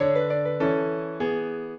piano
minuet6-5.wav